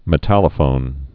(mĭ-tălə-fōn)